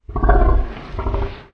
dragonclosing.ogg